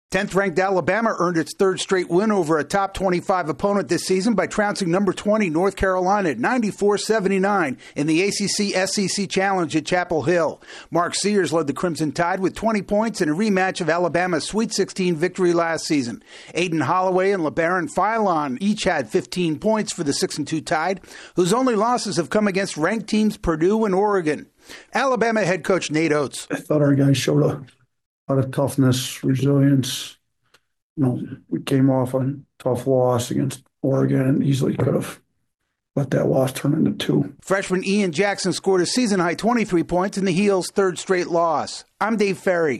Alabama improves to 3-2 versus ranked teams this season. AP correspondent